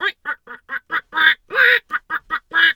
Animal_Impersonations
duck_2_quack_seq_01.wav